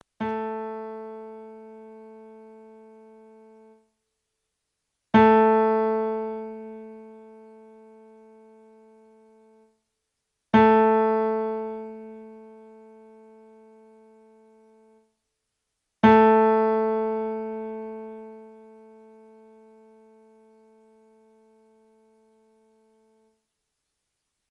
Wenn Sie auf die folgenden Links klicken, hören Sie, wie die Saiten klingen und können ihre Violoncello danach stimmen:
A-Saite (mp3):
cello_a.mp3